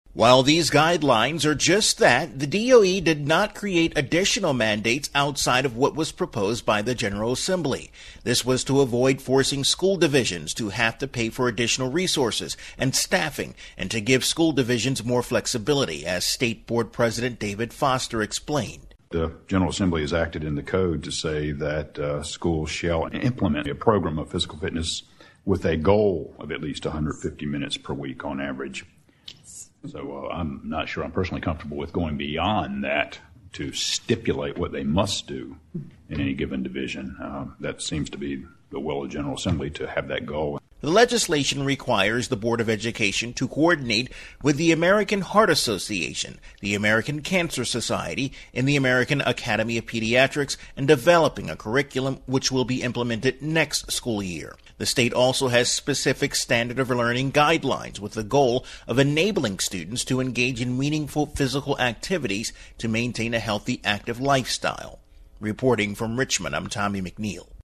This entry was posted on November 22, 2013, 4:57 pm and is filed under Daily Capitol News Updates.